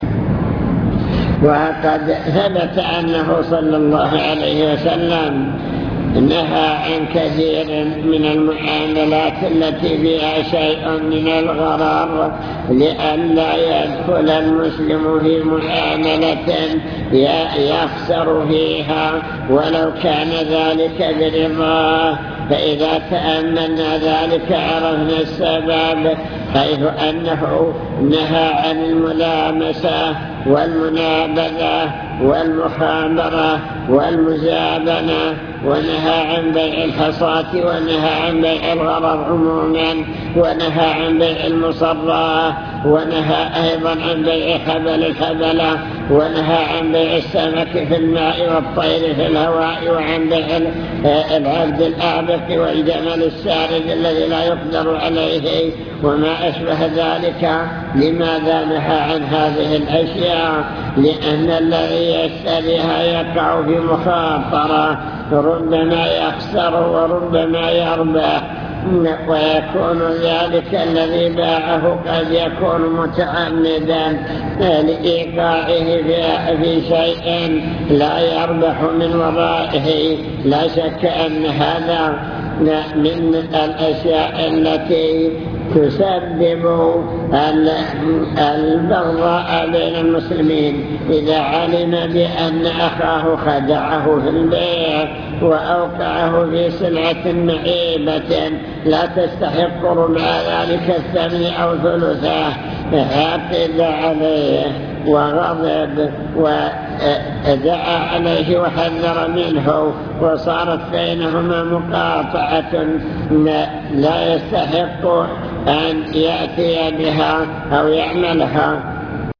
المكتبة الصوتية  تسجيلات - محاضرات ودروس  محاضرة بعنوان المكسب الحلال والمكسب الحرام